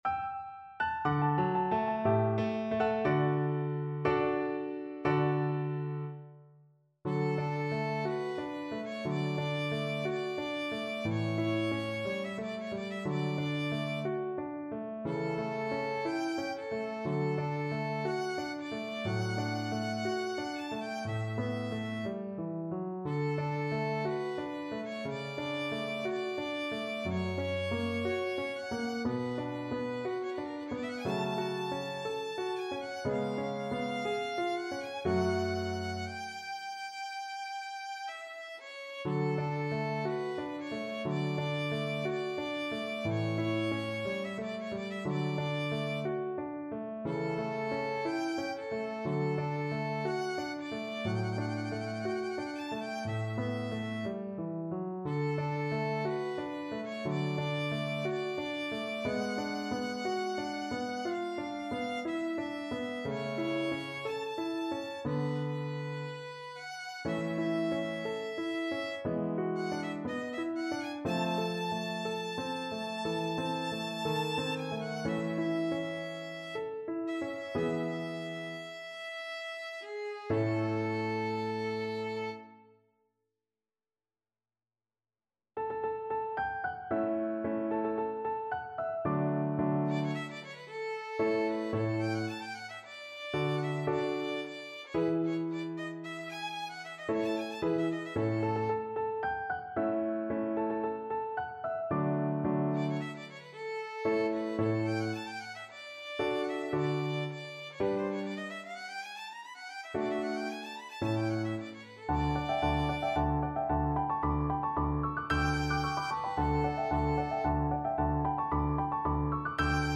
Violin
D major (Sounding Pitch) (View more D major Music for Violin )
Andante =c.60
2/4 (View more 2/4 Music)
Classical (View more Classical Violin Music)